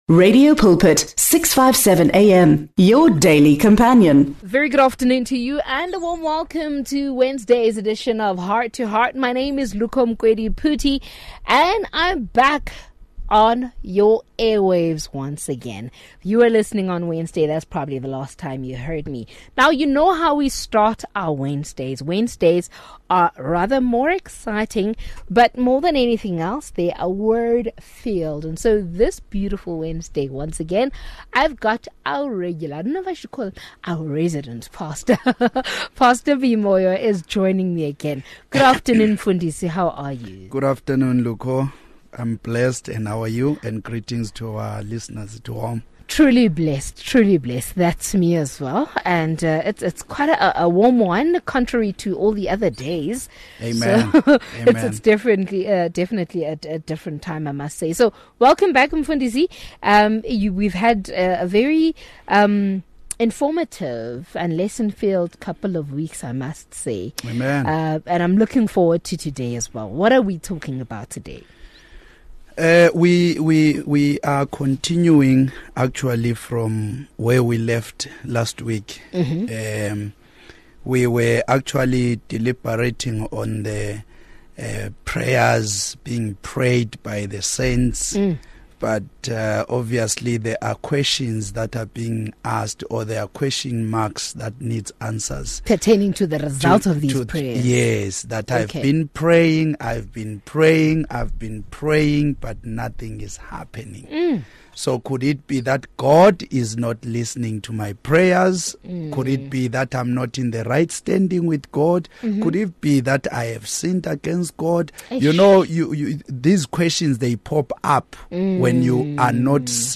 Heart to Heart is a magazine show on Radio Pulpit that brings you teachings, gospel music and advice.
To keep the content fresh, inspiring and from different perspectives, we have three presenters, each with their unique style.